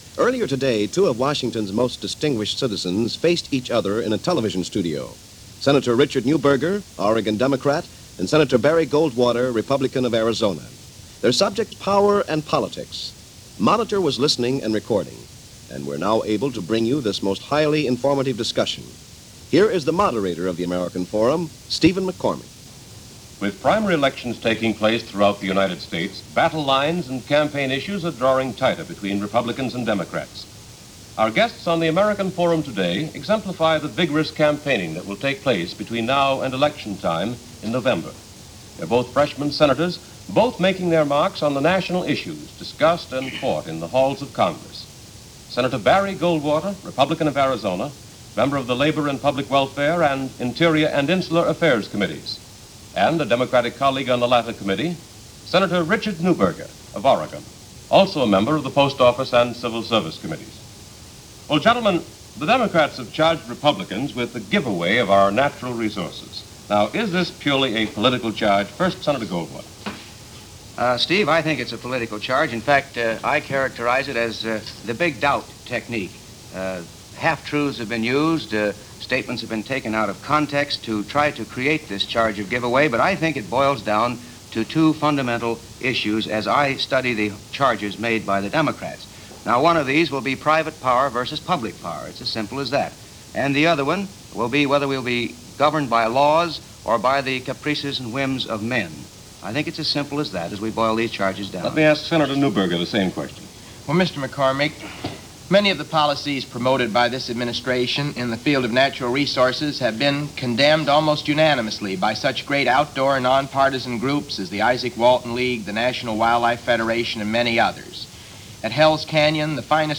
A discussion on campaign financing in 1956.